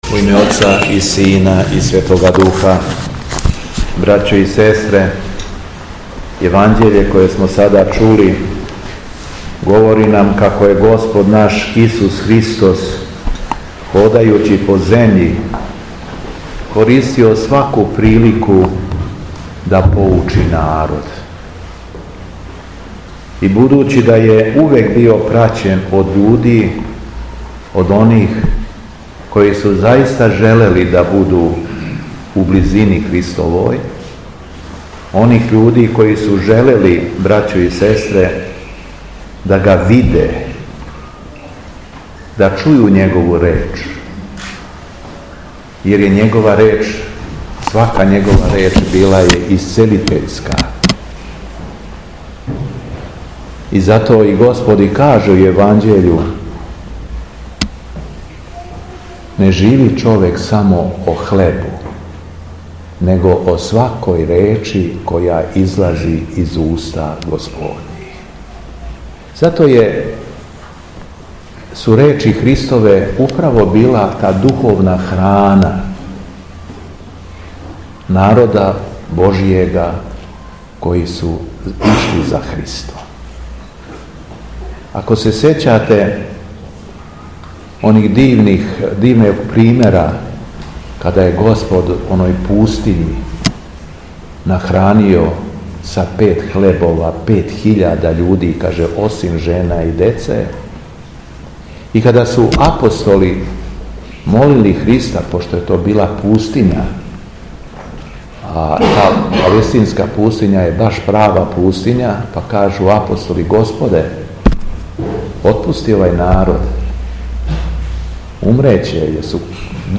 Беседа Његовог Преосвештенства Епископа шумадијског г. Јована
Након прочитаног зачала из Светог Јеванђеља Епископ се обратио верном народу речима: